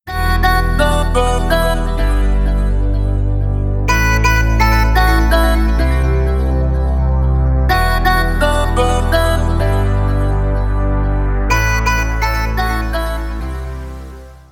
забавные
спокойные
без слов
на смс